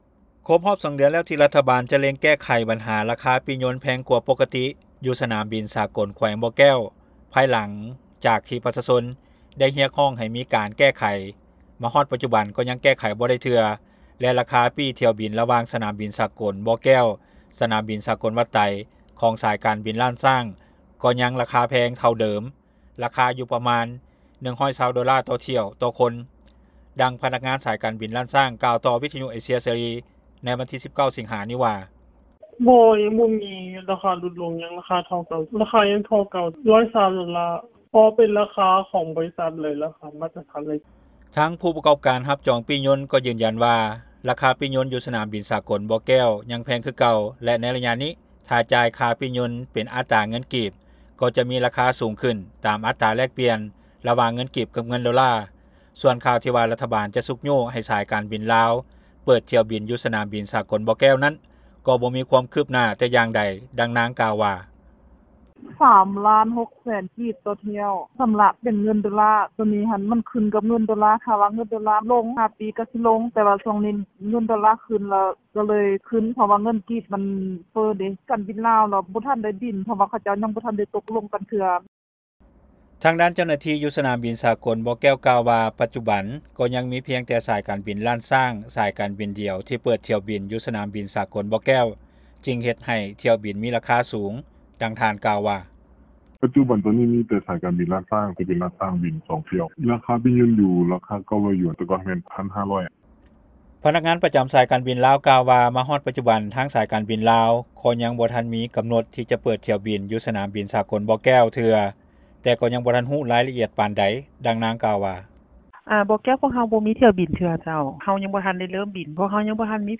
ດັ່ງພະນັກງານສາຍການບີນລ້ານຊ້າງກ່າວຕໍ່ວິທຍຸເອເຊັຽເສຣີໃນວັນທີ 19 ສິງຫາ ນີ້ວ່າ.